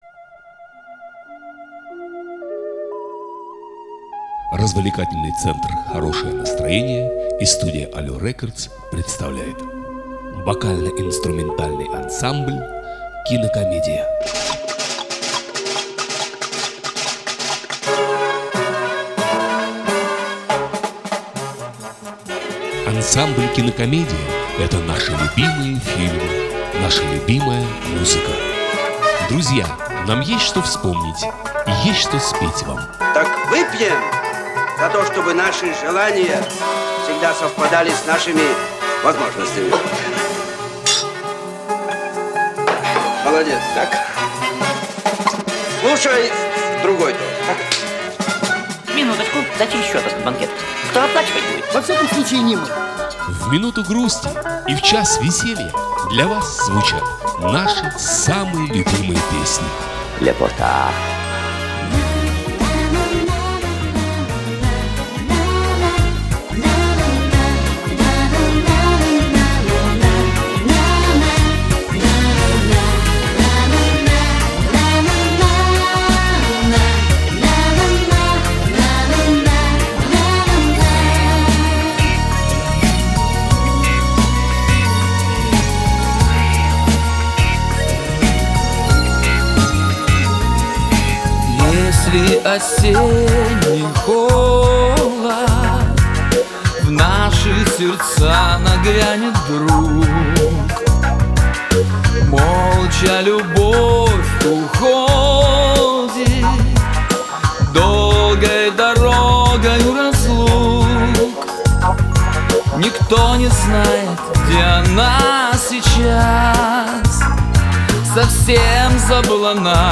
вокал, гитара
бэк вокал
альт-саксофон, клавишные
ударные, перкуссия